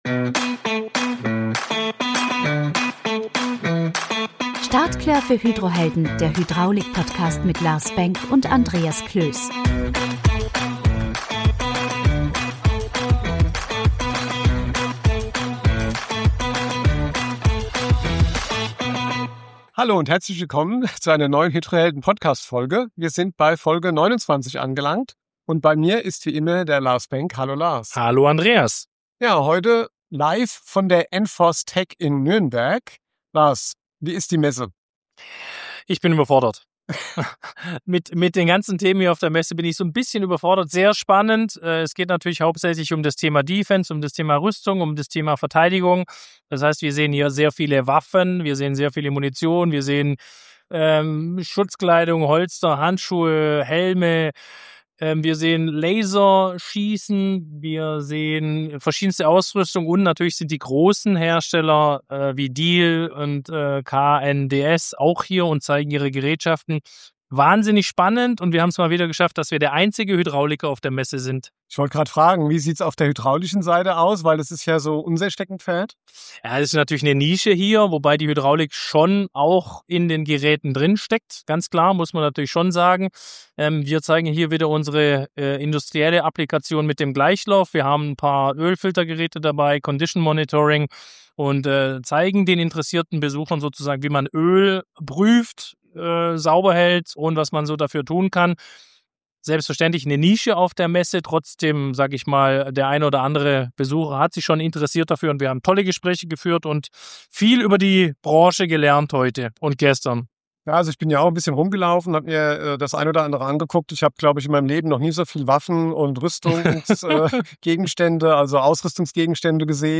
Hydrohelden Folge 27 - Live von der Enforce Tac in Nürnberg